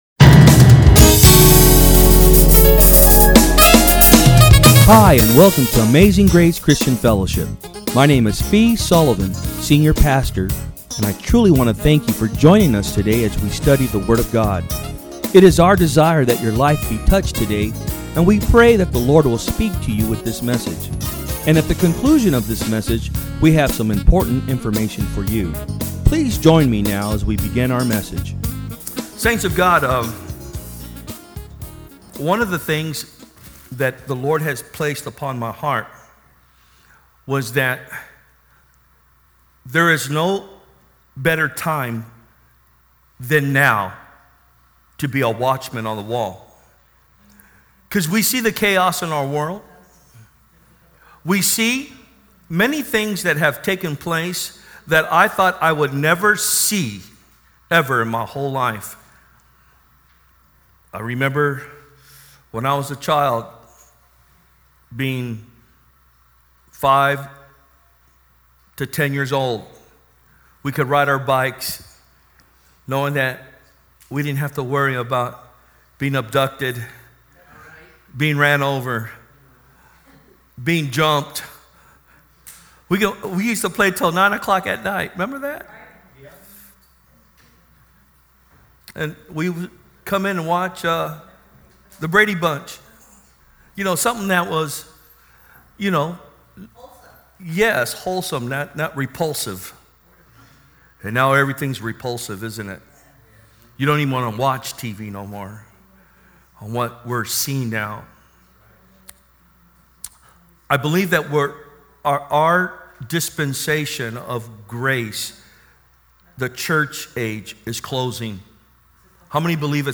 From Service: "Sunday Am"